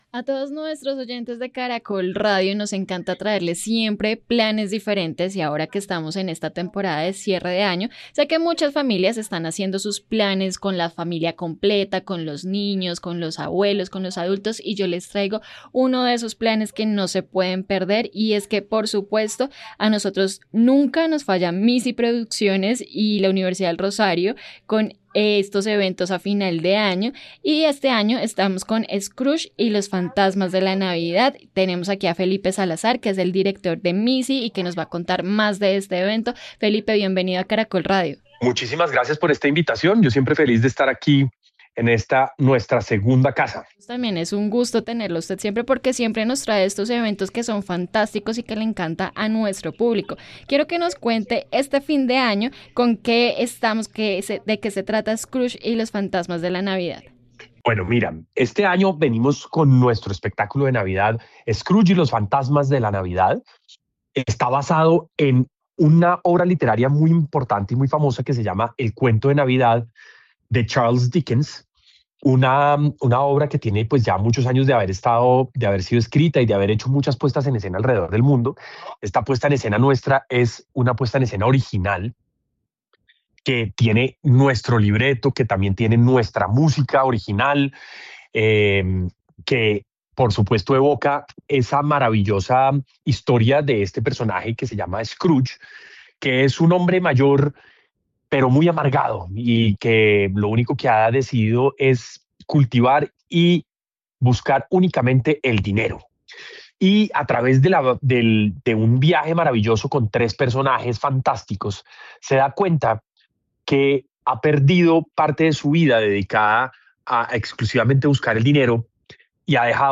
En entrevista con Caracol Radio